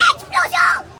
explode2.ogg